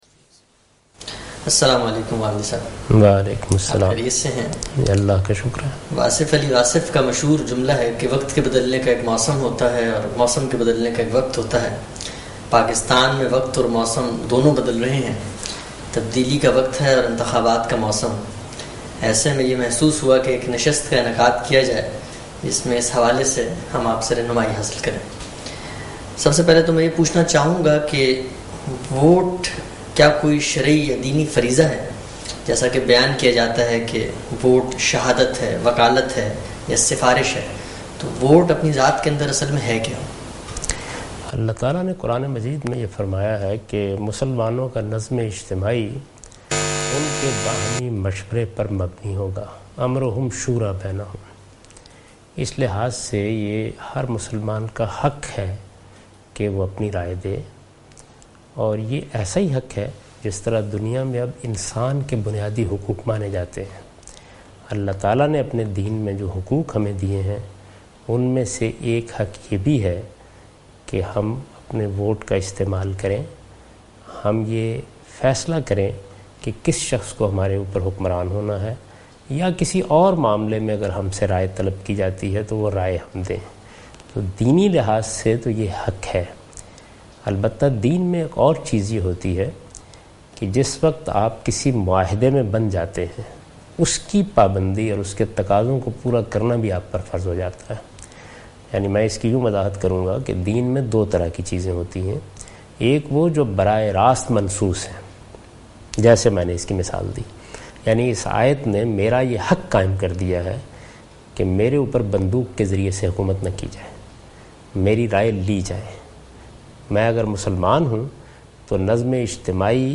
How should we vote? what should be the criteria on which we should vote? An Important QnA session with Javed Ahmad Ghamidi before elections.